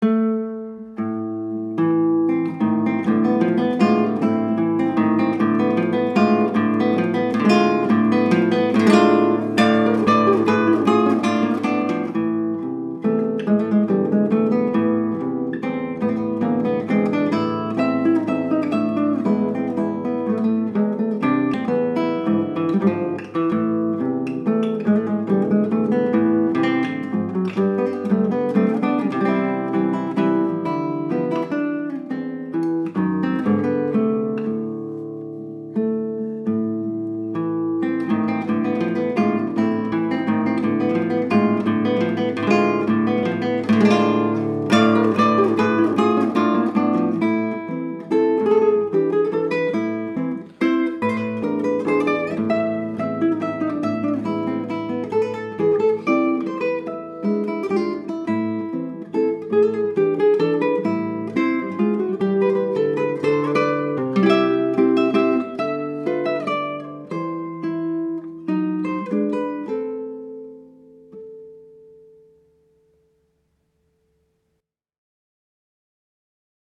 گیتار در دو اُکتاو
نوازنده گیتار کلاسیک